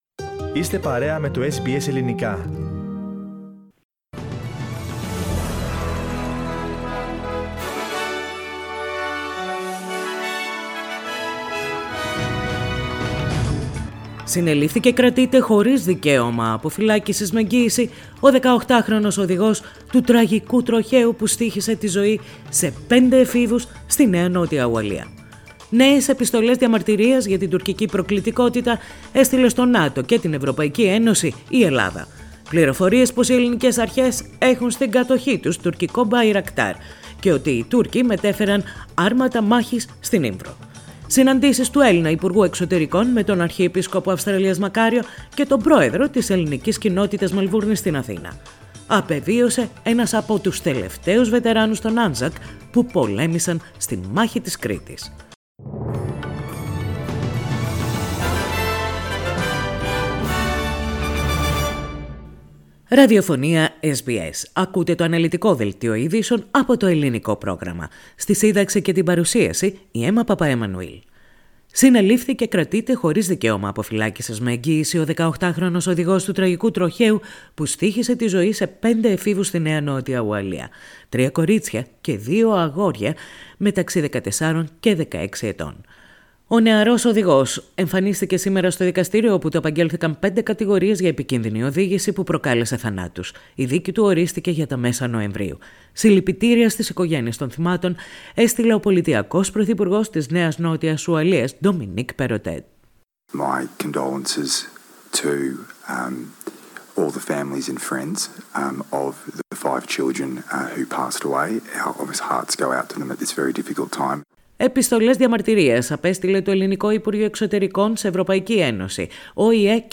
Δελτίο Ειδήσεων - Πέμπτη 8.9.22